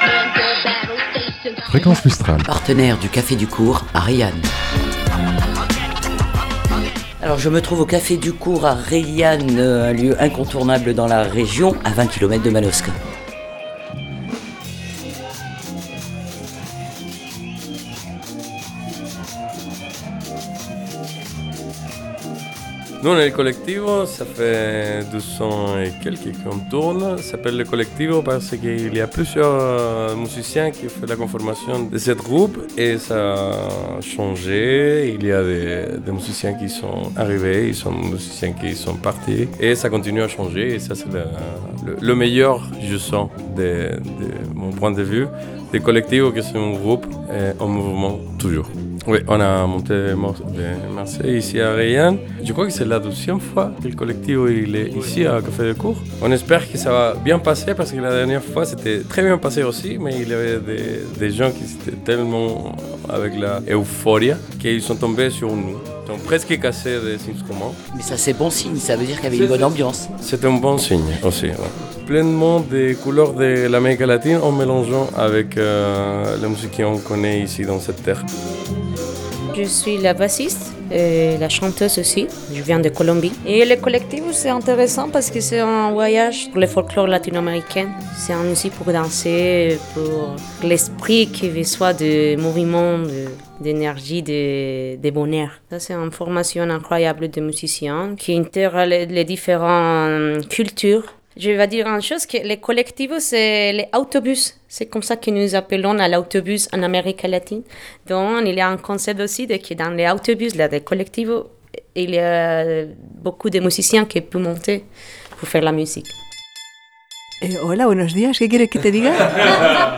Retrouvez le live du vendredi soir !
Vendredi dernier, la soirée fut explosive avec le groupe "El Colectivo" ! Le groupe est né de la rencontre entre 2 chanteurs guitaristes chiliens et des percussionnistes marseillais. Le répertoire, nous balade dans toute l’Amérique Centrale et les caraïbes avec des thèmes revisités, le travail des voix et de la percussion s’harmonise parfaitement pour vous faire danser avec des textes poétiques.